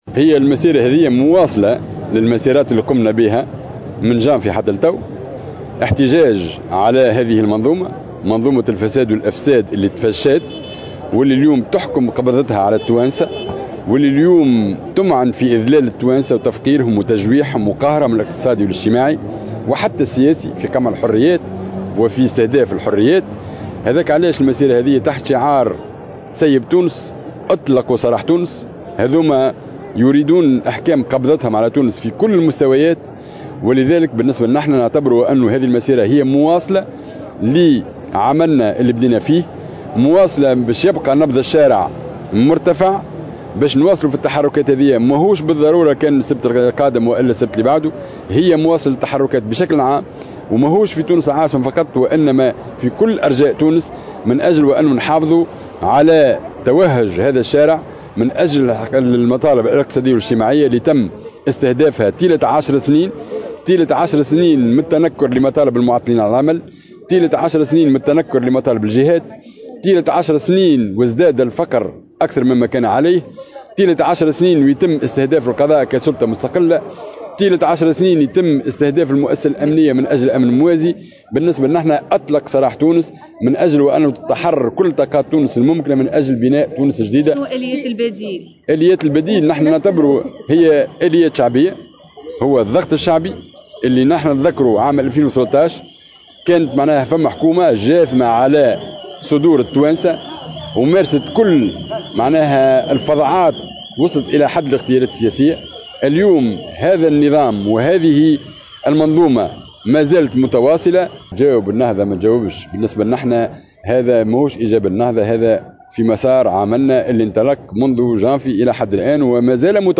في تصريح للجوهرة أف أم، على هامش مسيرة بشارع الحبيب بورقيبة